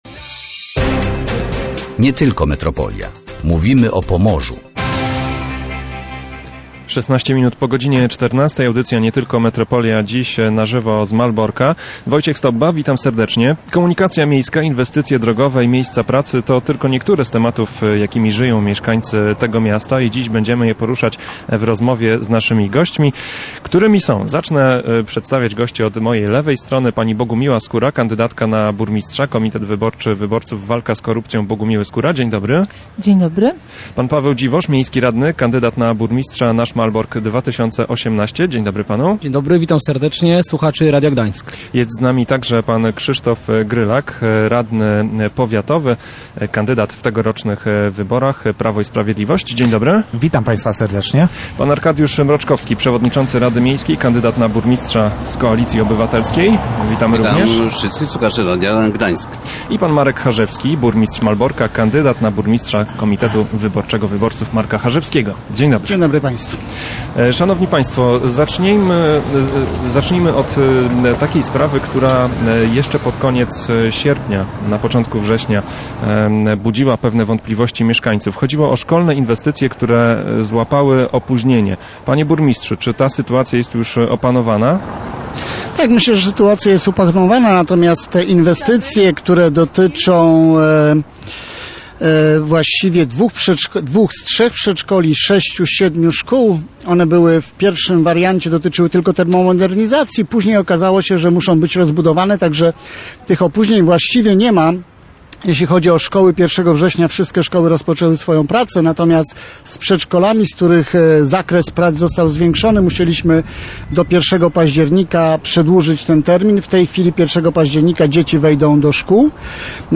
Komunikacja miejska, inwestycje szkolne i drogowe. Debata wyborcza w Malborku
Komunikacja miejska, inwestycje drogowe i miejsca pracy – to tylko niektóre z tematów, jakimi żyją mieszkańcy Malborka. Zostały one poruszone podczas debaty samorządowców ramach audycji Nie tylko Metropolia.